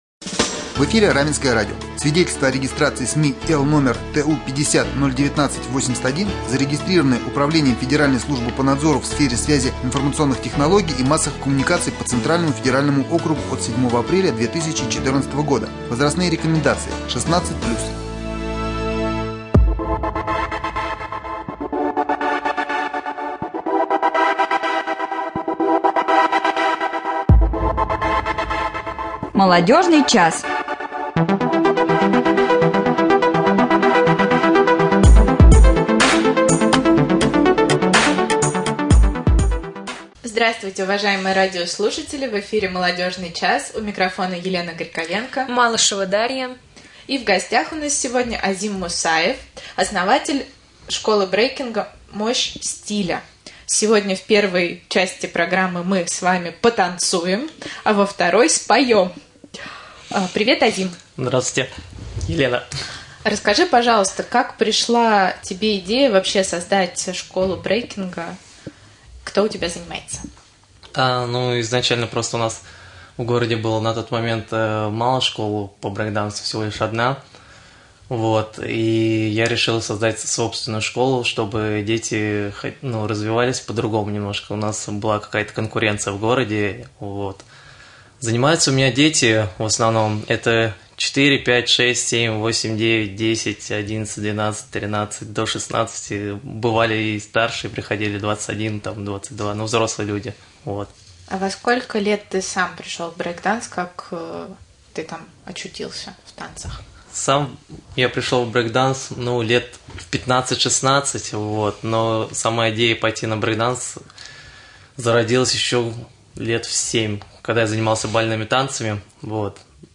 Гости студии